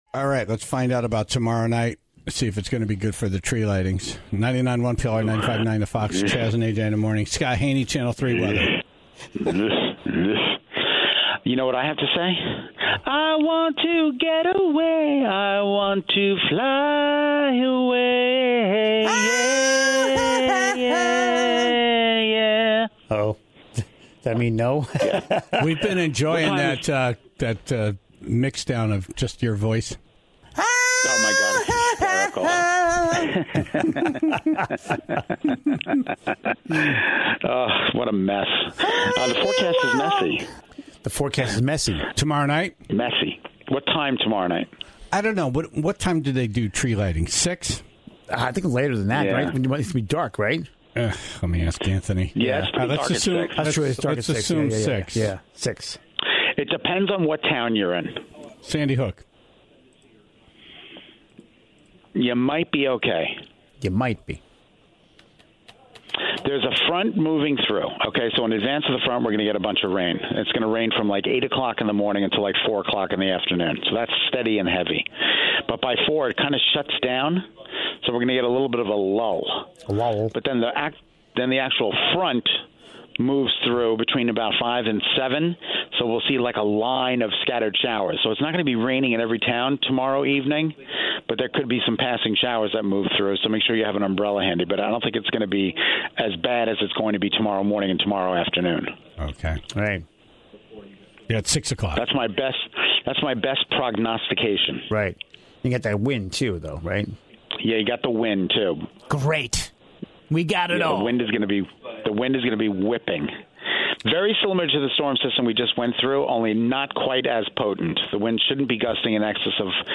(16:33) The top 5 cars most likely to make it 250,000 miles, according to the internet. Plus, the Tribe calls in to share the cars they've been able to rely on forever.